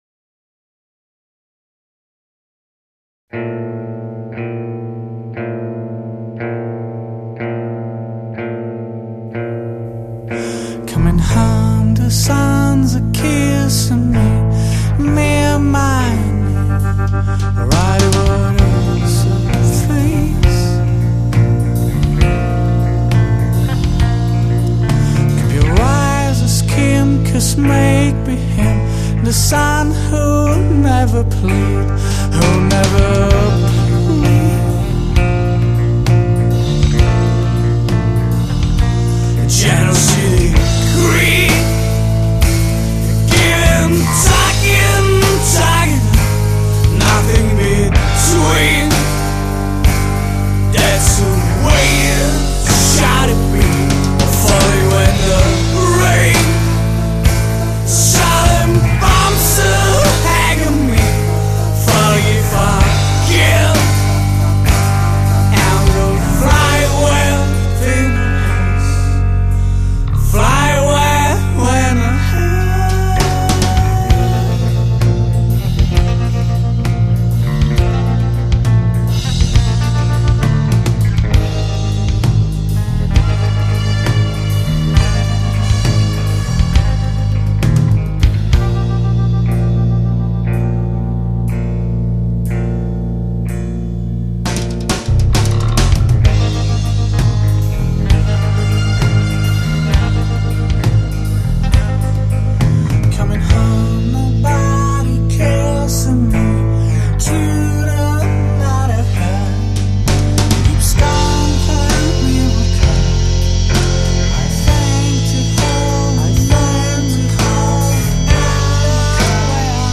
zpěv, kytara
basová kytara
bicí
Trumpeta